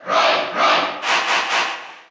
File:Roy Cheer Russian SSBU.ogg
Category: Crowd cheers (SSBU) You cannot overwrite this file.
Roy_Cheer_Russian_SSBU.ogg